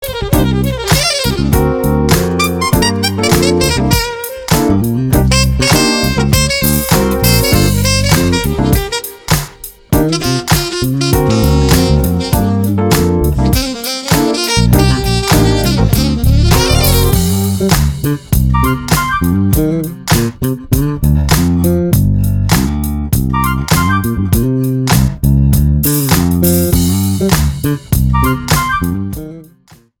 EASY LISTENING  (02.36)